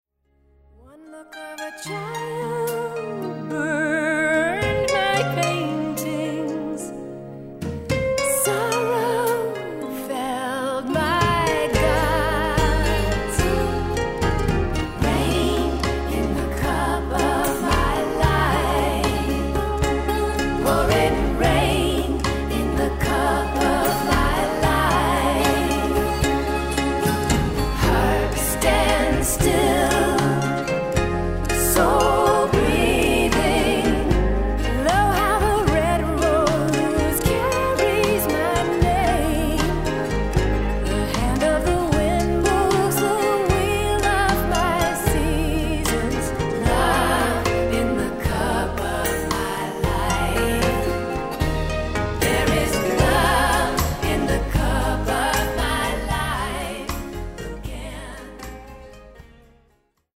Recorded & mixed at Powerplay Studios, Maur – Switzerland